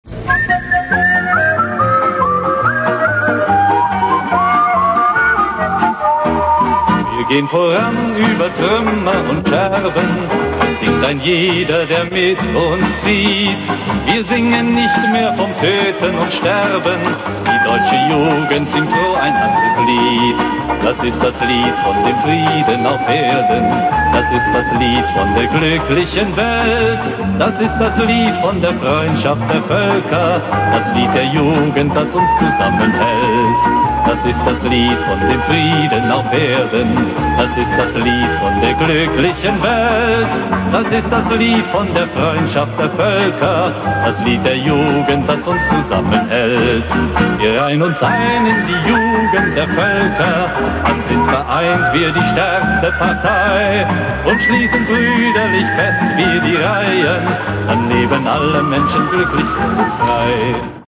Фонограмма вырезана из р/передачи
на немецком
Это ГДРовская послевоенная песня